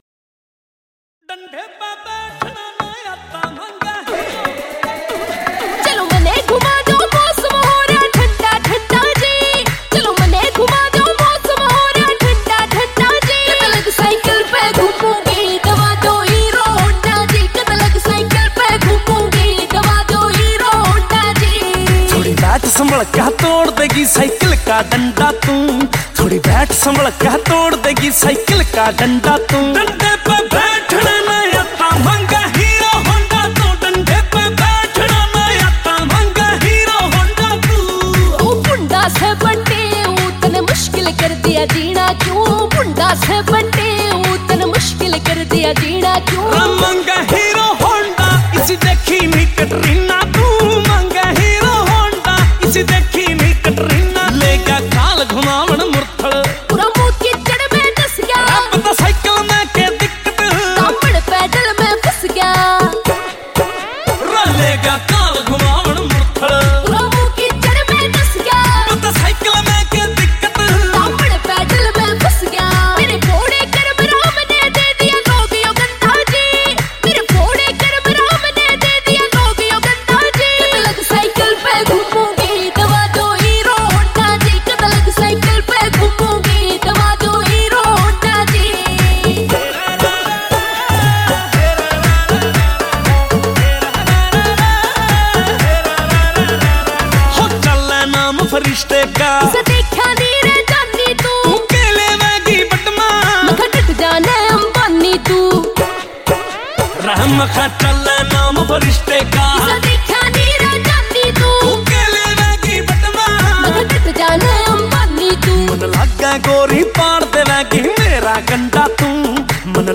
Releted Files Of Haryanvi